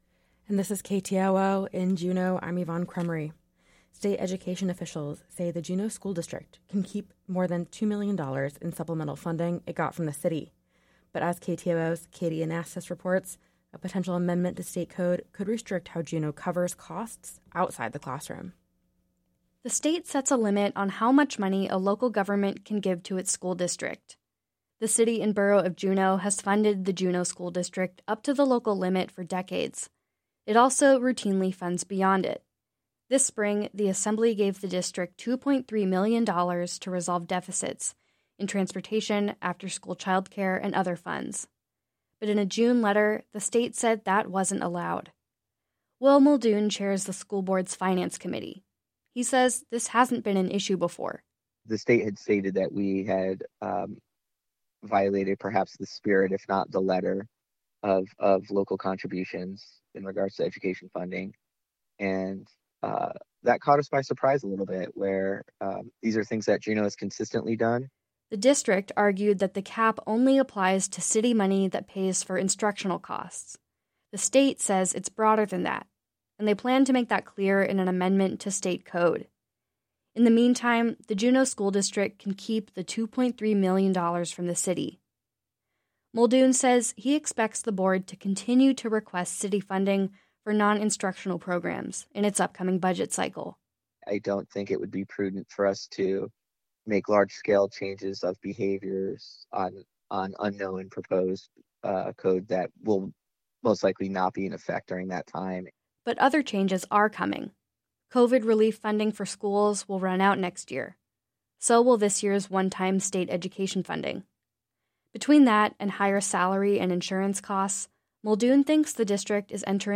Newscast – Monday Dec. 11 2023